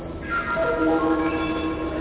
pariscdg announcement alert
描述：Paris CDG Airport announcement alert Chime
标签： fieldrecording
声道立体声